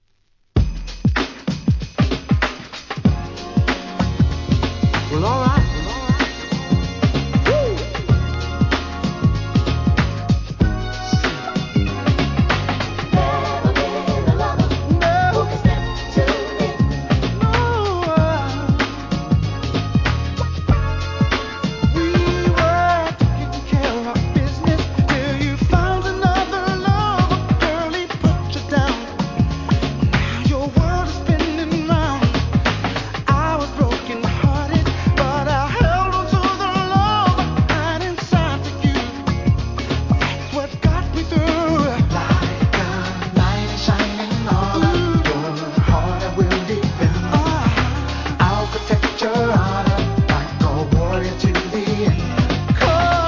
NEW JACK SWING